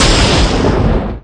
Thunder7.ogg